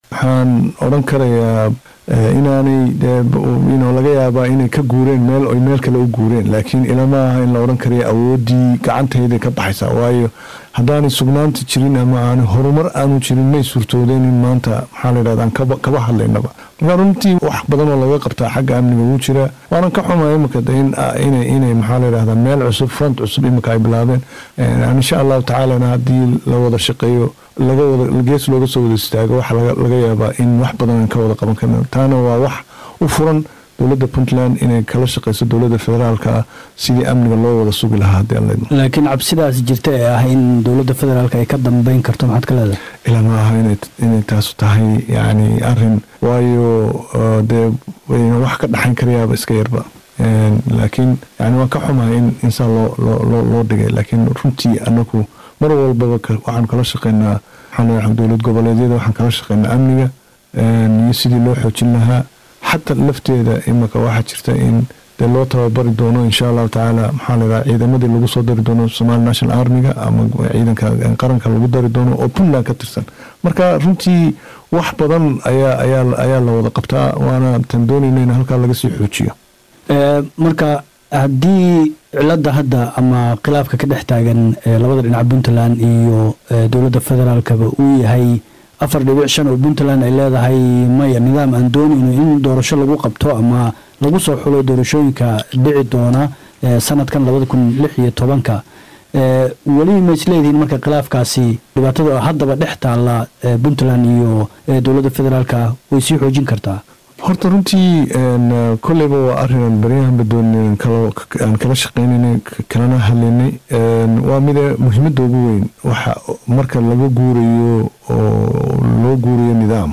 Ra’iisul Wasaare kuxigeenka Xukuumadda Soomaaliya Maxamed Cumar Carte oo wareysi siiyay Laanta afka Soomaaliga ee BBC-da ayaa sheegay dagaalyahanada gaaray Puntland ay yihiin kuwo meel ka tagay, meel kalena u guuray.
DHAGEYSO-WAREYSI_-Xukuumada-Soomaaliya-oo-ka-jawaabtay-Eedeymaha-kaga-yimid-Madaxda-Puntland-_.mp3